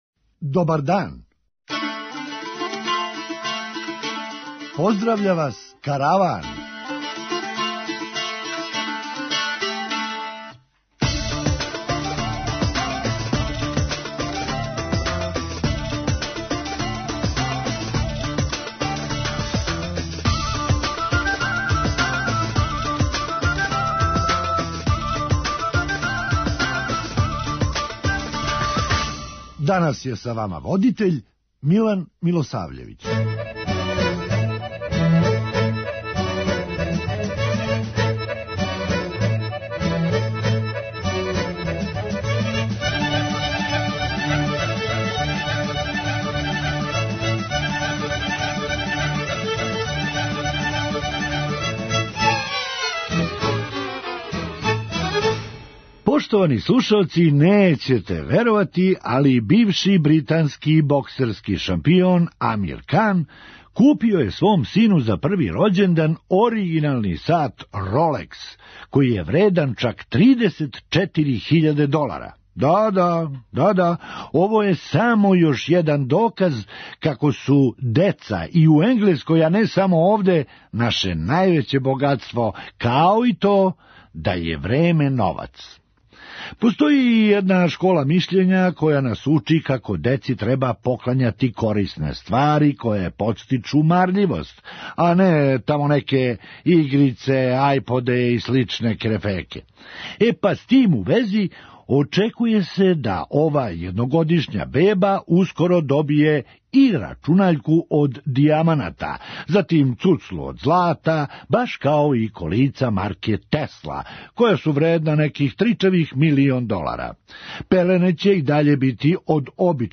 Хумористичка емисија | Радио Београд 1 | РТС